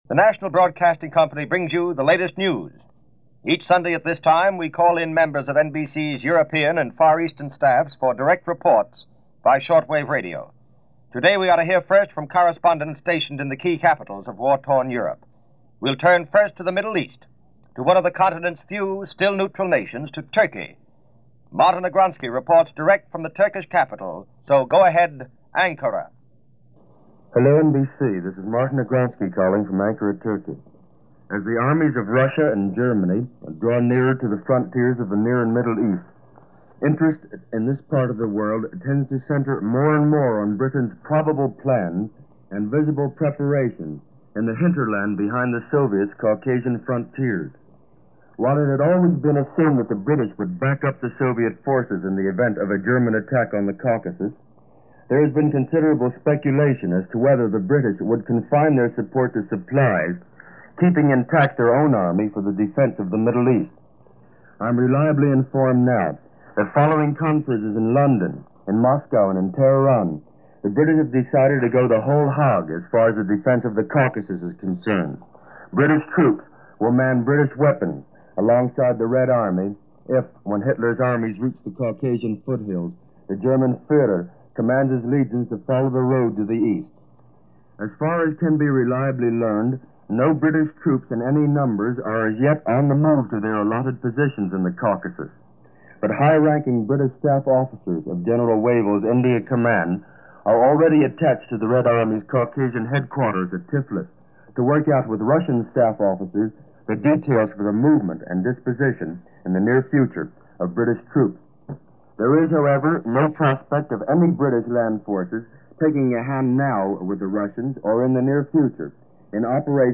Reports from the fighting fronts on the state of war this week in 1941.